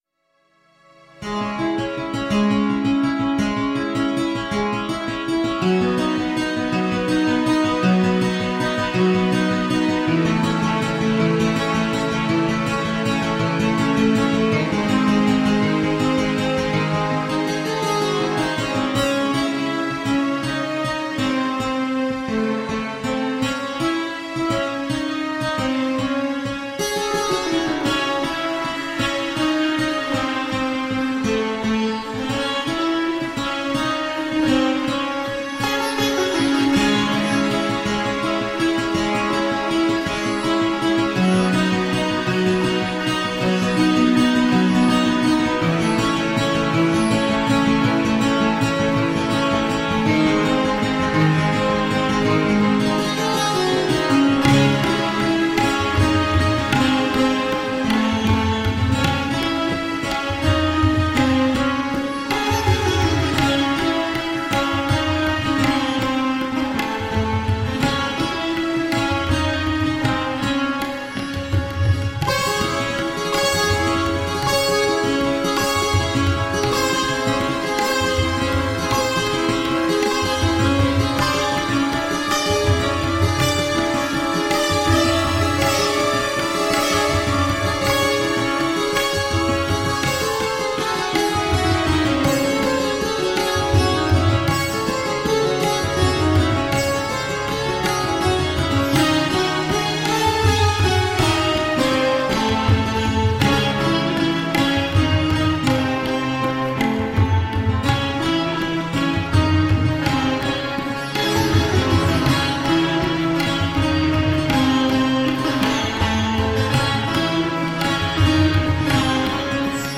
Electro indian sitar.
Tagged as: World, Indian, Indian Influenced, Sitar